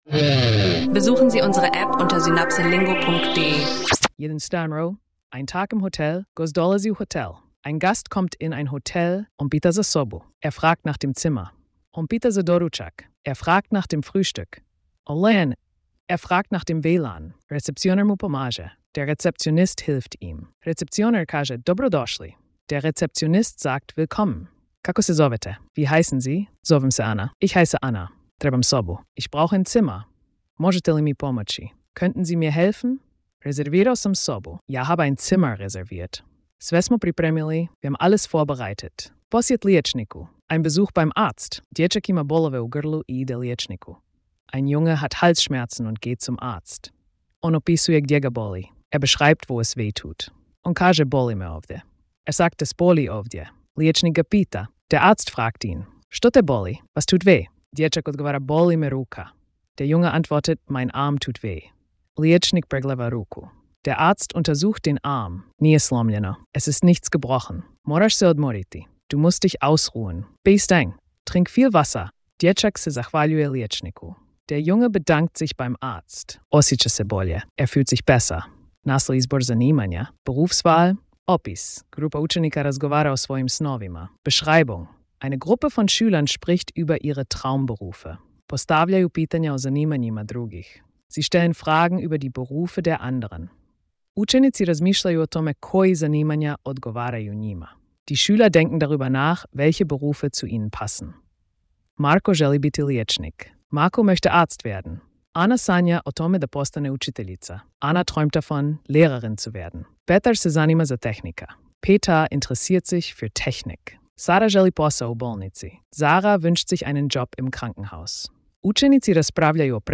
Lerne Kroatisch mit Alltagsdialogen: Perfekt für Anfänger und Urlauber!